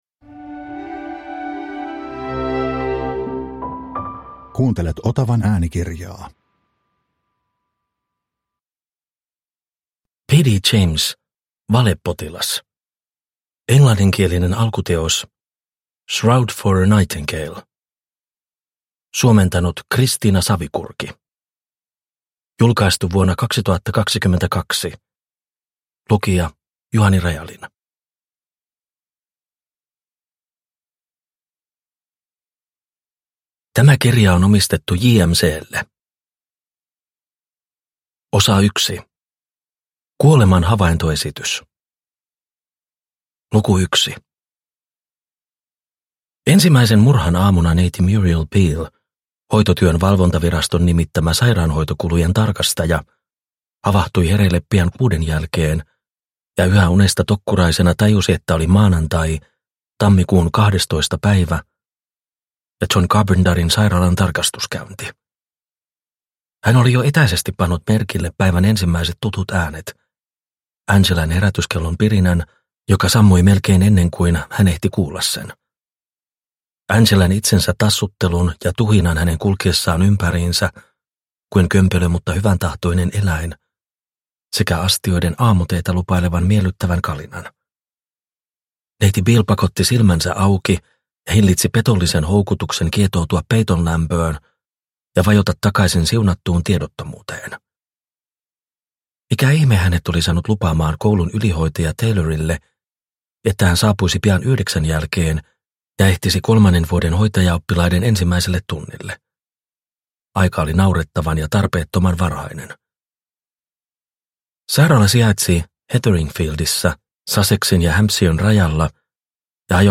Valepotilas – Ljudbok – Laddas ner